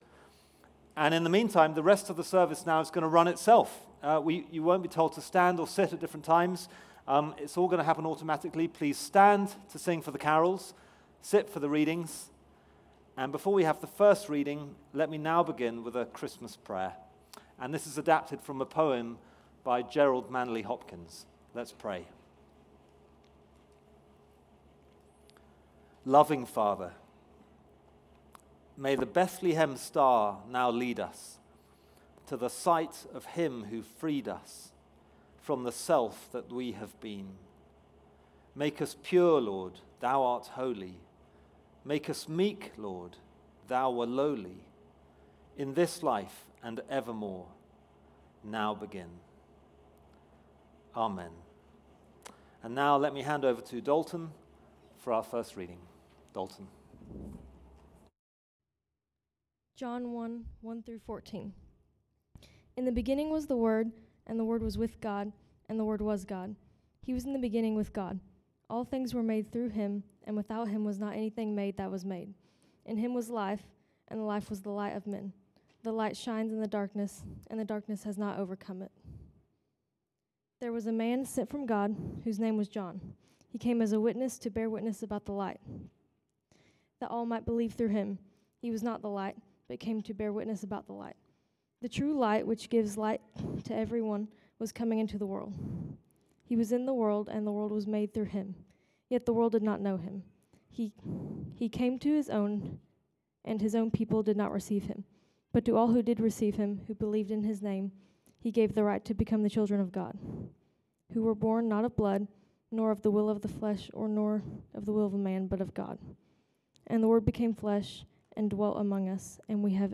Christmas Eve Service